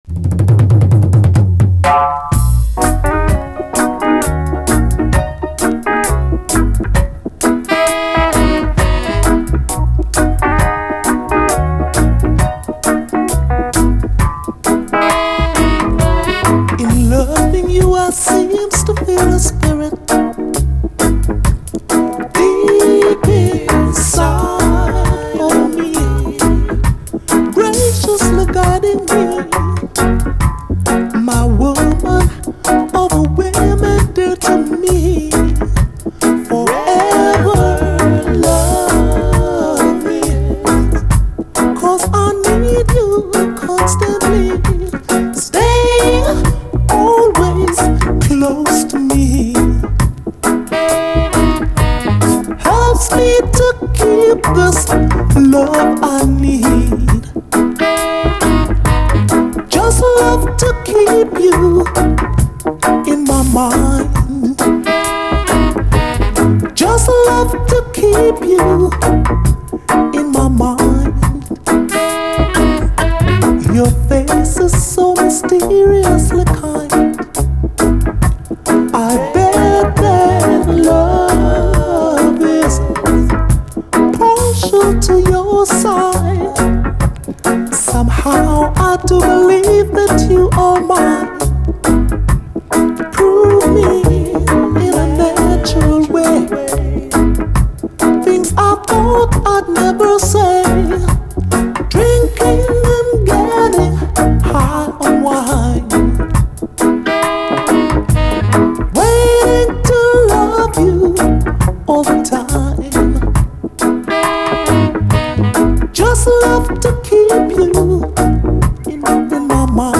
REGGAE
グレイト・スウィート・レゲエ！
トロリと柔らかい質感のメロウ・ソウル的グレイト・スウィート・レゲエ！
後半はインスト。